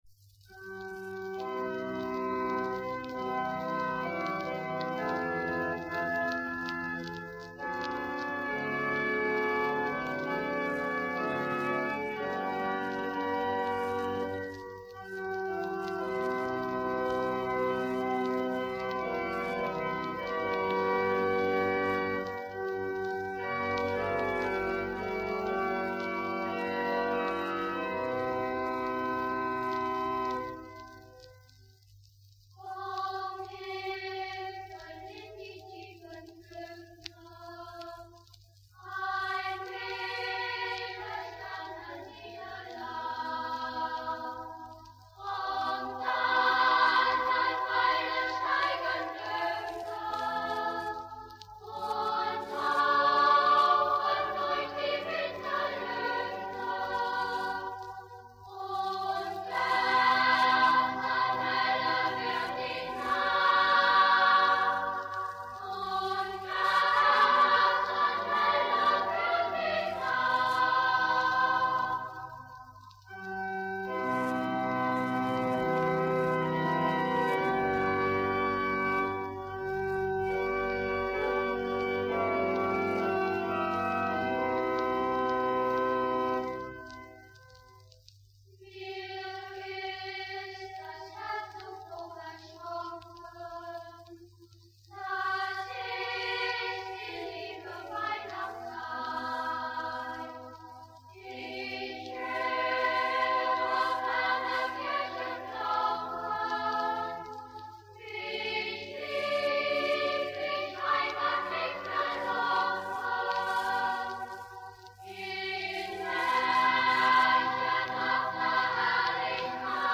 Etwa Mitte der 50er Jahre wurde im Hamelner Münster eine Schallplatte mit zwei wunderschönen Weihnachtsliedern aufgenommen.
Bei der Qualität beachten Sie bitte, dass es sich um Schallplattenaufnahmen aus den 50-er Jahren handelt.
Wir haben die Aufnahmen bereits - soweit möglich - aufgearbeitet.